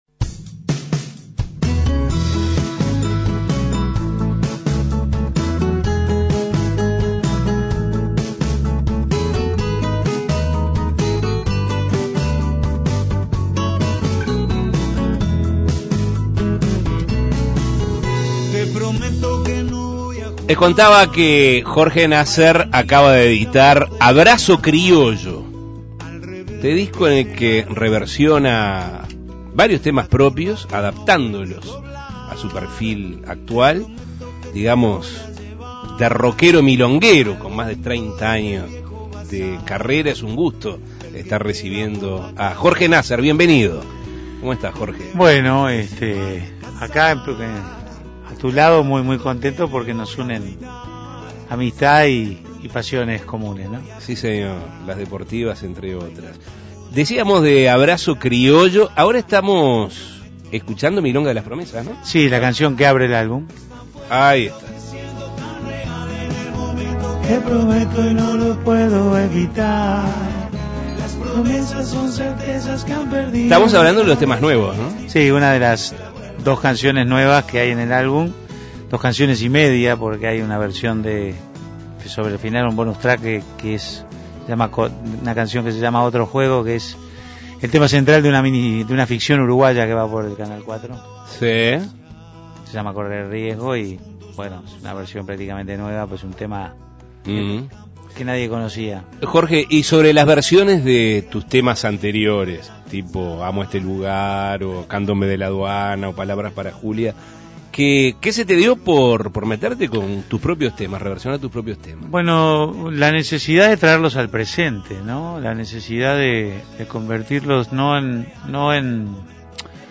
Entrevistas Un rockero milonguero Imprimir A- A A+ Jorge Nasser conversó con Asuntos Pendientes.